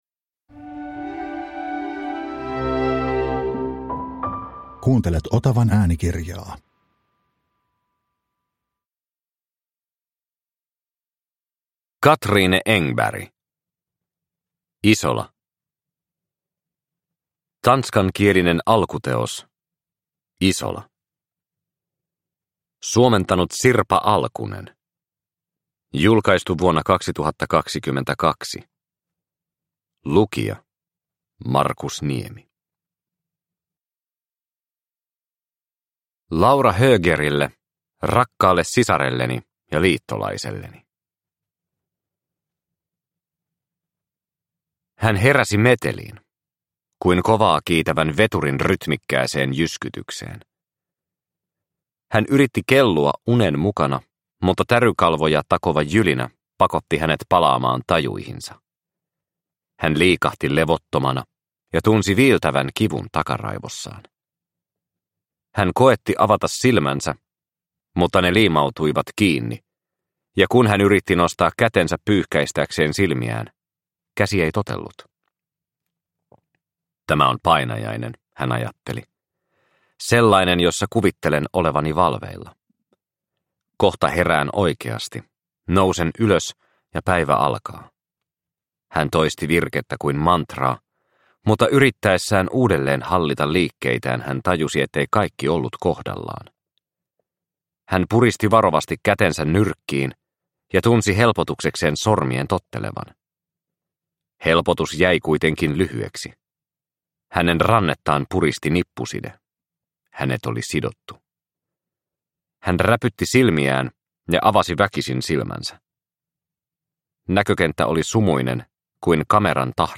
Isola – Ljudbok – Laddas ner